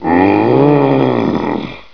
Zombie walk VA